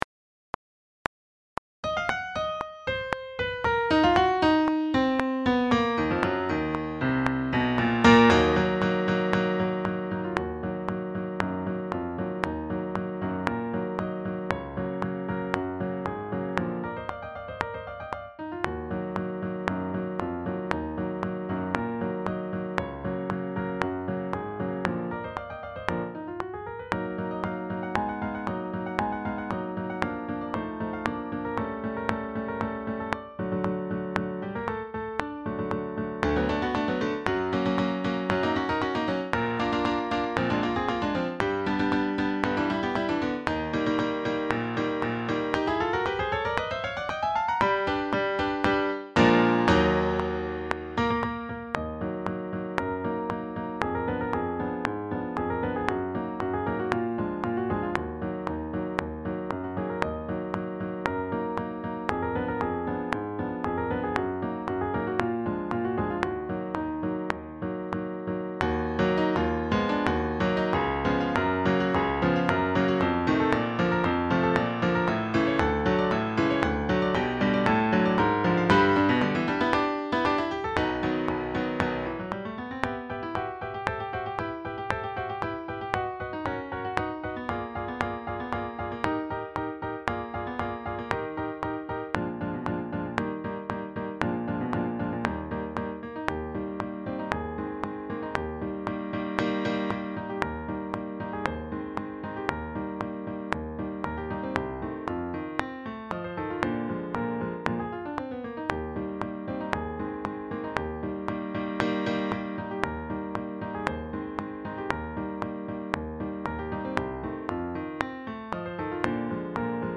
Sax Octets
Backing track